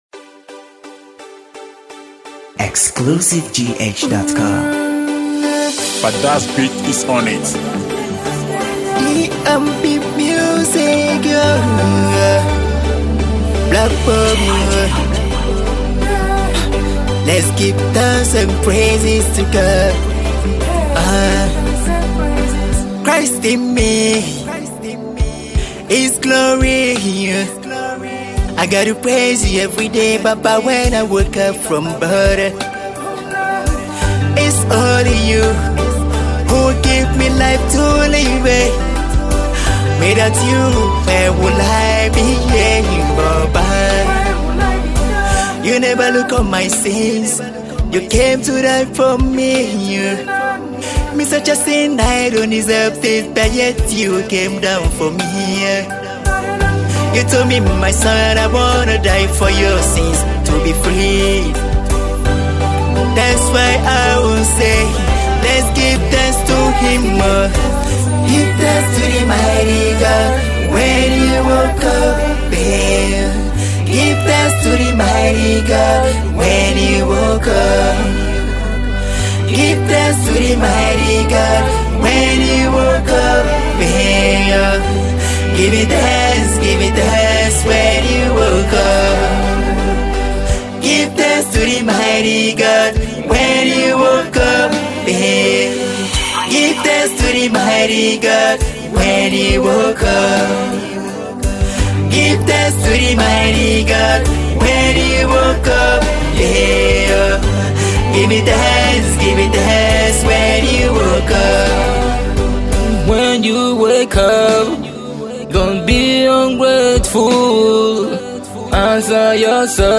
RnB gospel